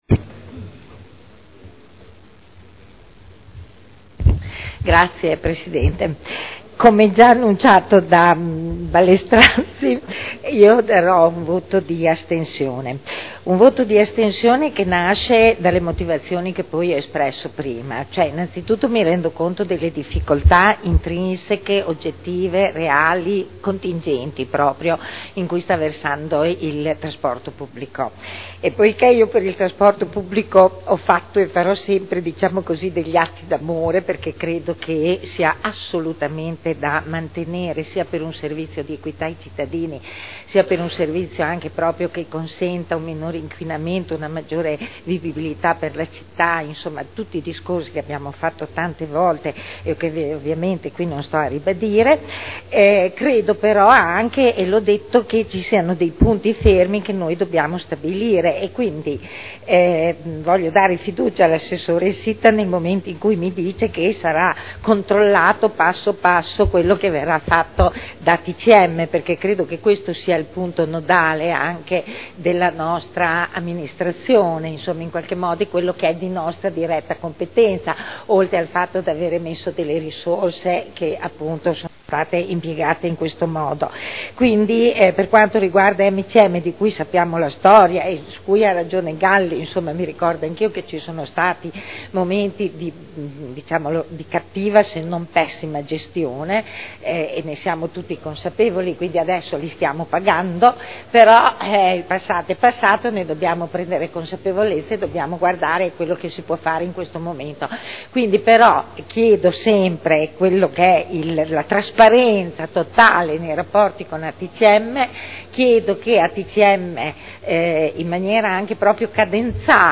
Seduta del 21/02/2011. Indirizzi per la gestione del trasporto pubblico locale a seguito del patto per il trasporto pubblico regionale e locale in Emilia Romagna per il triennio 2011/2013 – aumenti tariffari per il Comune di Modena – Approvazione dichiarazioni di voto